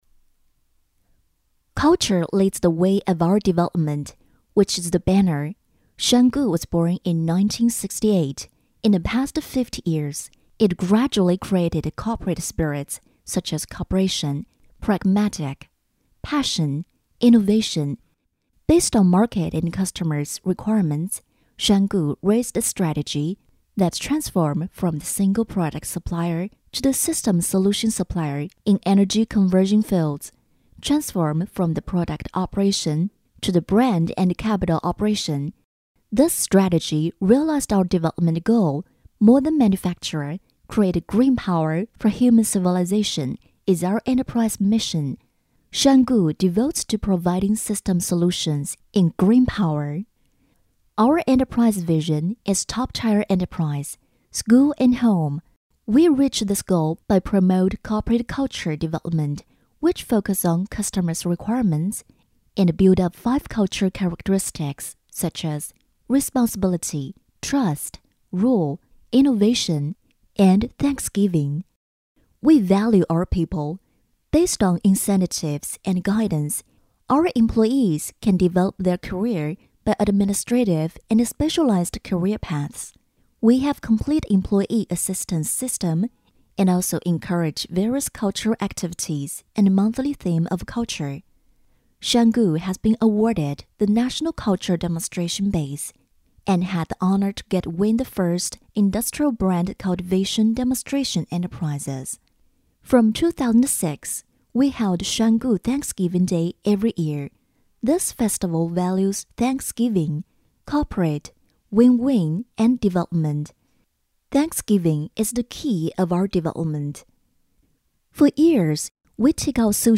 配音风格： 磁性 年轻 自然